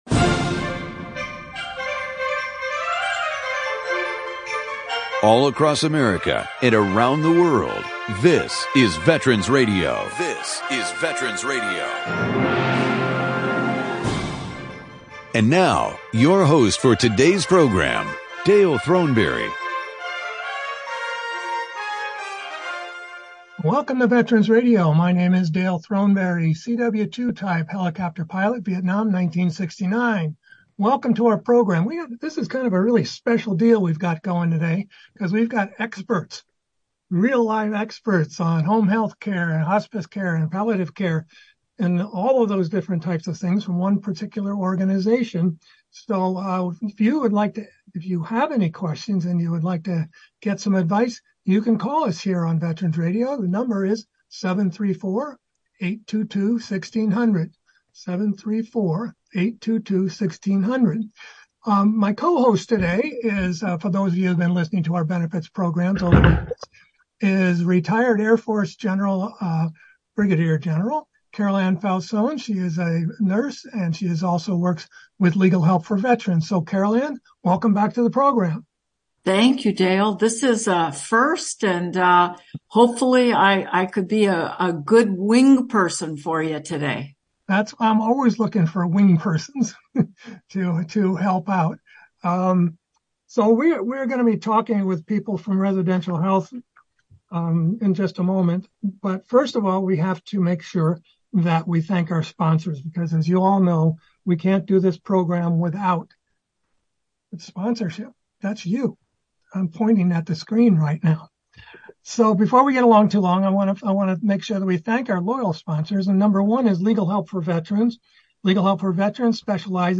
(4:00pm CT, 3:00pm MT, 2:00pm PT) This program will be recorded and re-broadcast later in the week in California and Minnesota.